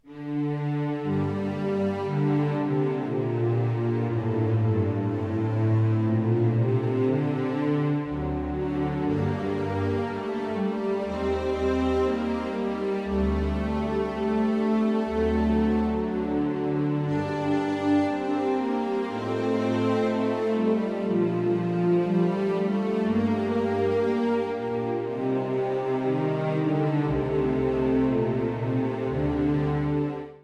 Fassung für Streicher und Hörner
Neue Musik
Ensemblemusik
Kammerorchester/Ensemble
Horn (4), Streichorchester
Es ist ein äußerst ruhiges und harmonisches Stück.